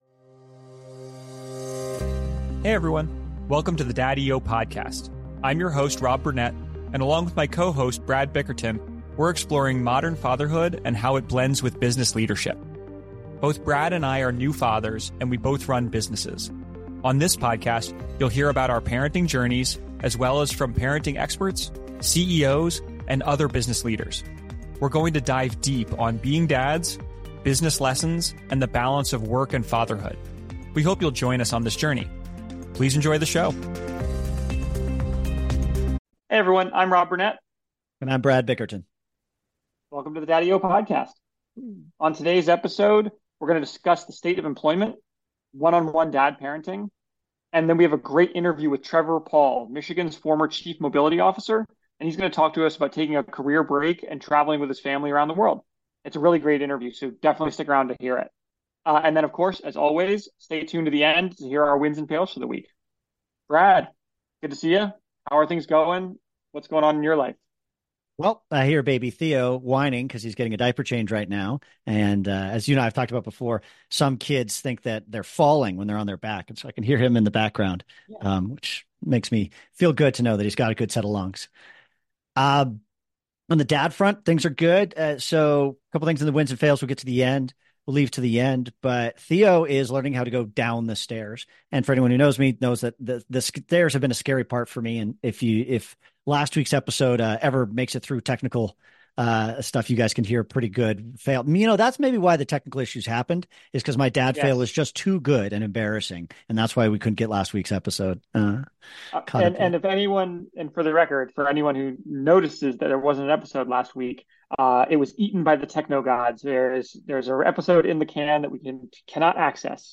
And stay tuned as always to the end to hear our wins and fails from this week.